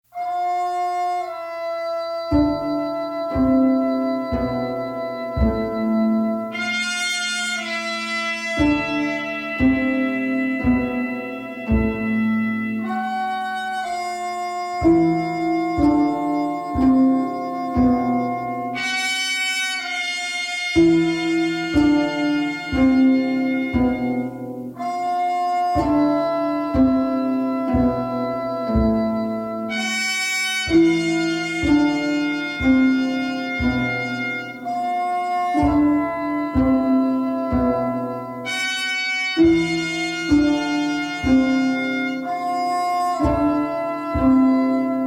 minimal, obsessive style
in clean monaural sound